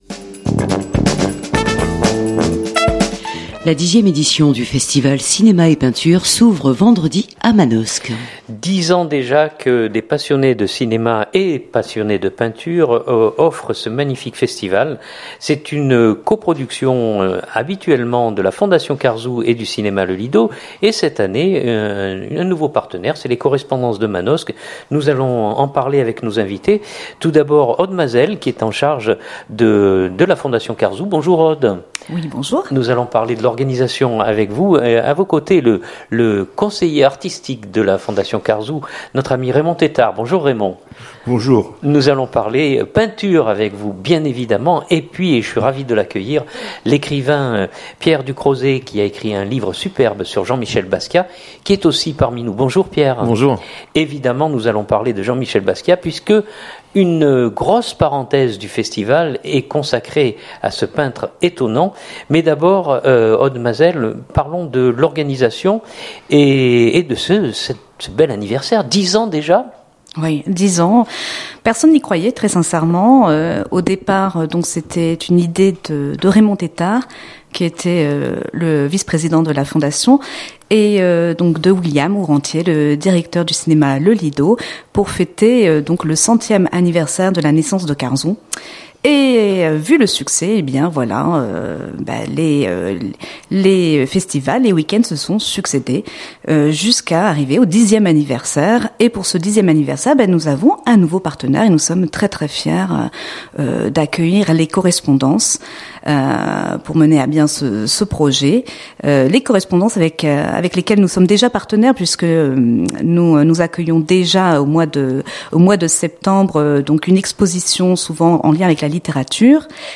Belles rencontres et croisement des médiums sur le plateau de Fréquence Mistral .